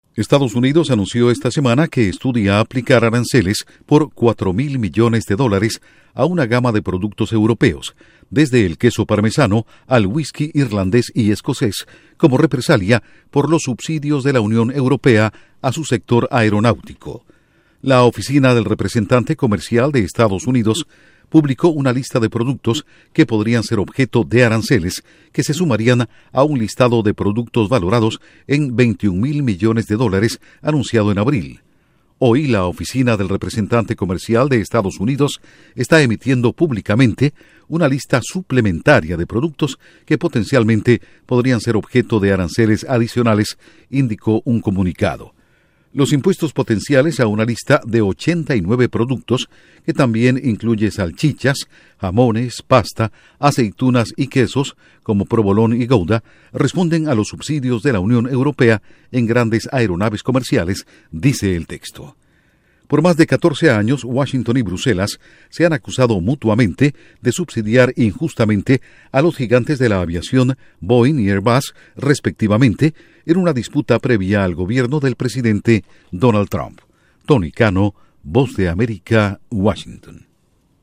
Estados Unidos evalúa imponer aranceles a quesos y whisky de la Unión Europea. Informa desde la Voz de América en Washington